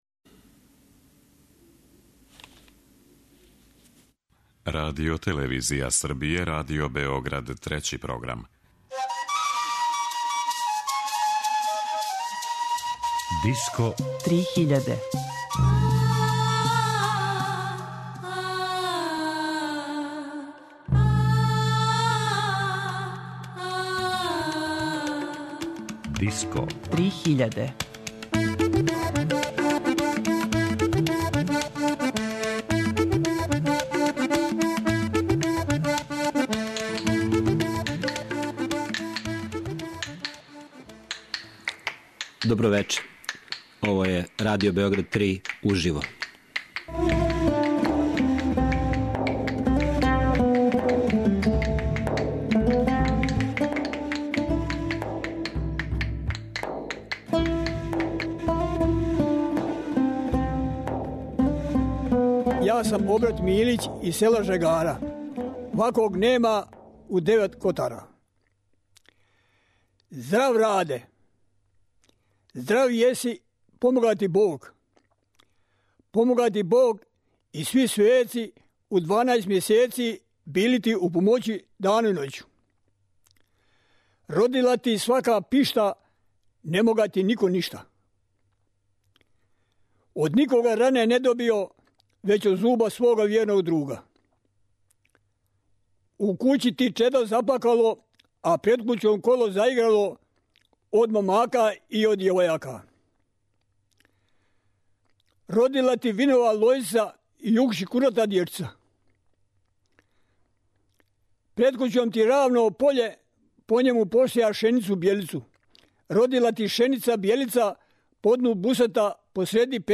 diplar i guslar
Pevačica